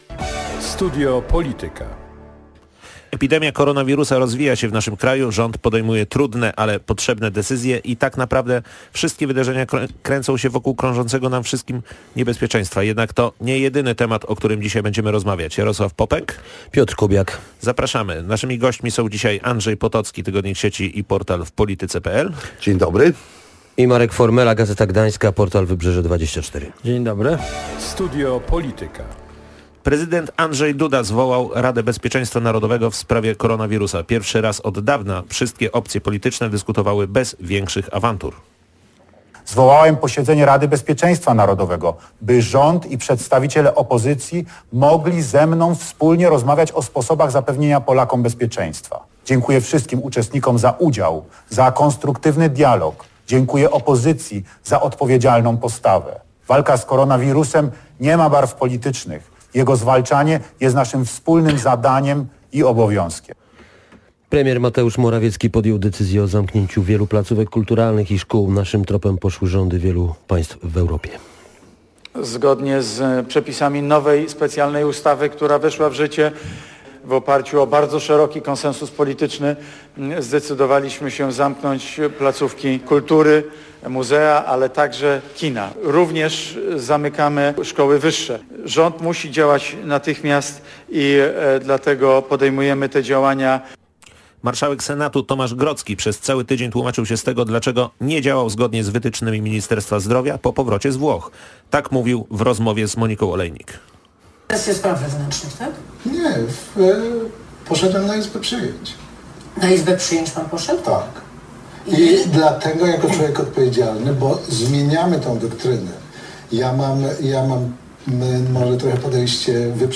Między innymi o pracy szefa resortu zdrowia rozmawialiśmy w audycji Studio Polityka.